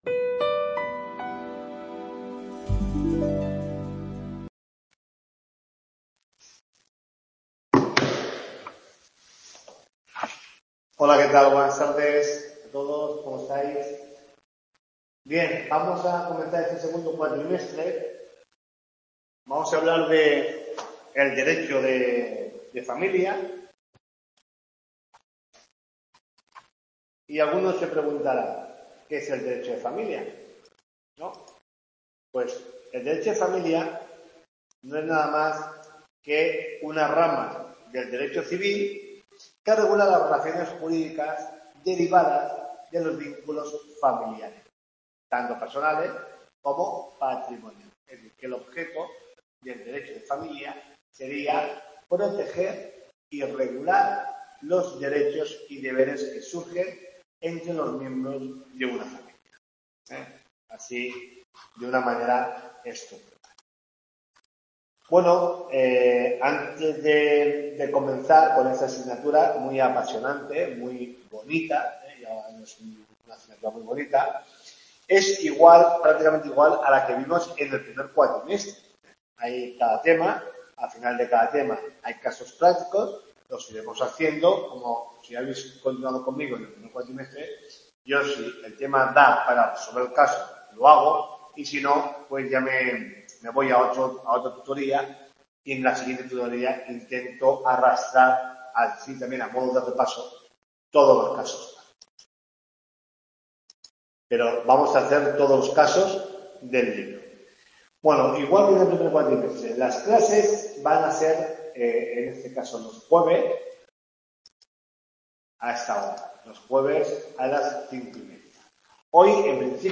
TUTORIA 1